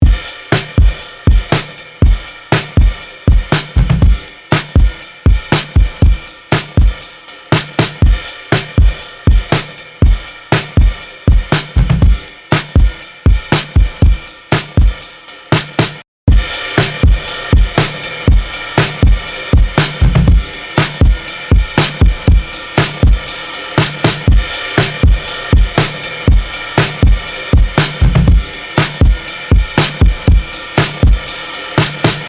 Drums | Preset: Drum Bus Lift (DRY→WET)
Articulate-Drums-Drum-Bus-Lift-2.mp3